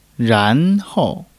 ran2--hou4.mp3